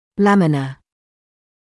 [‘læmɪnə][‘лэминэ]тонкая пластинка; тонкий слой